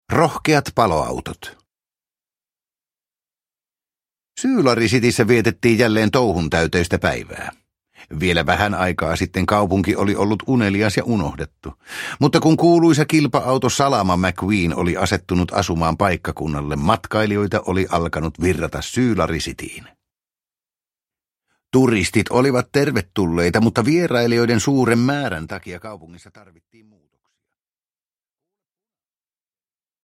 Pixar Autot. Rohkeat paloautot – Ljudbok – Laddas ner